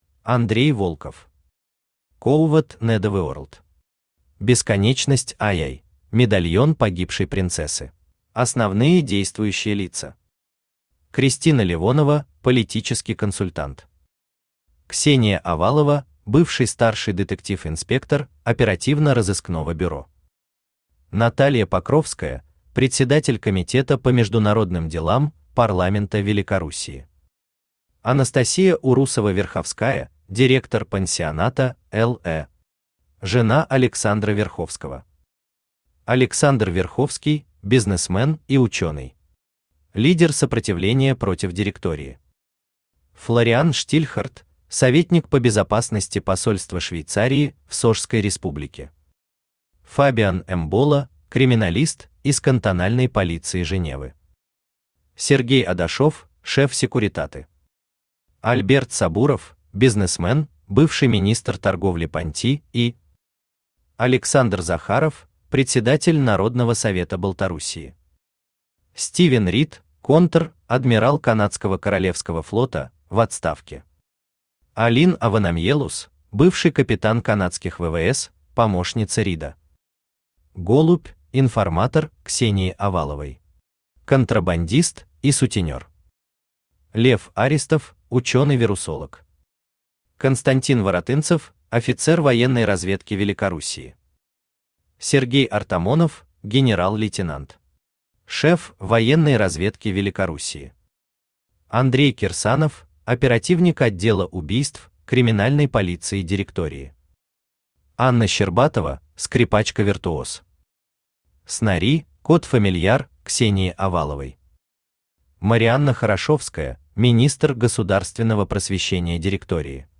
Аудиокнига Сovert Netherworld. Бесконечность II. Медальон погибшей принцессы | Библиотека аудиокниг
Медальон погибшей принцессы Автор Андрей Волков Читает аудиокнигу Авточтец ЛитРес.